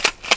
clickcli.wav